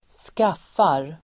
Uttal: [²sk'af:ar]